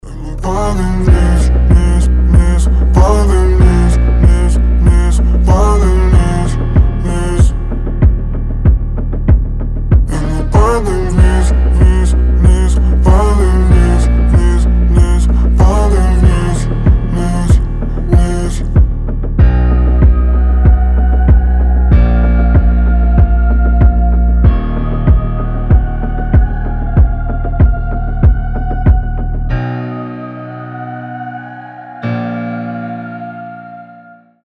• Качество: 320, Stereo
лирика
Хип-хоп
грустные
атмосферные